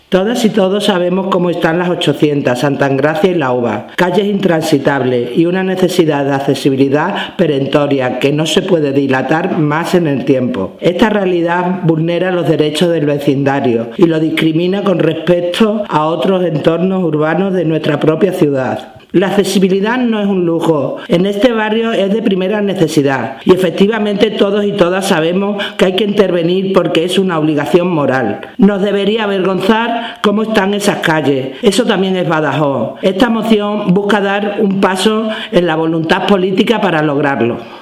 CORTE DE SONIDO SOBRE EL TEMA DE LA CONCEJALA CANDY ARCE GÓMEZ:
Candy-Arce-Gomez-sobre-accesibilidad-en-Las-800.mp3